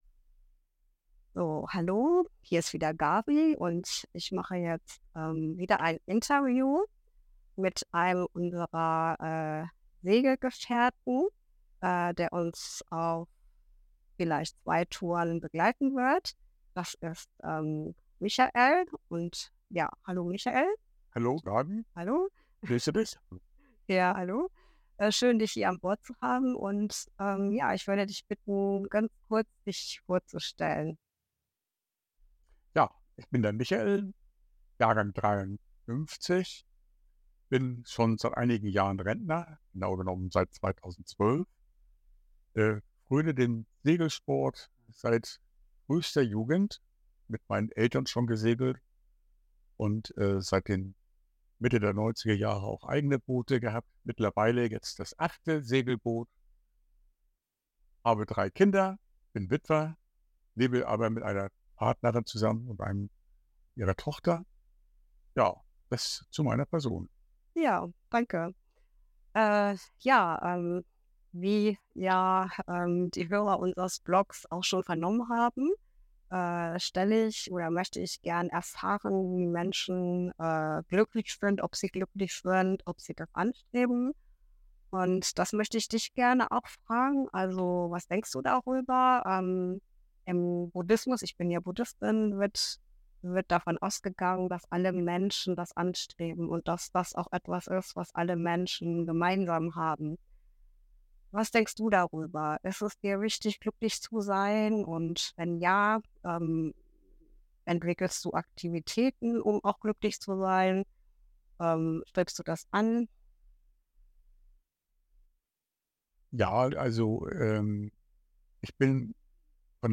Interviewreihe zum Thema Glück